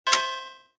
Звуки игровых автоматов, однорукого бандита для монтажа видео в mp3 формате.